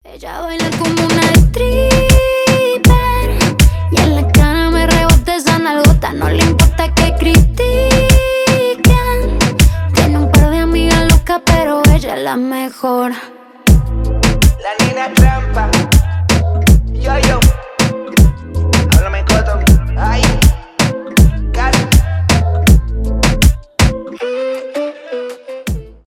танцевальные
реггетон